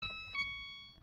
Звуки дверей автомобиля
Звук сигнала открытой двери ВАЗ Самара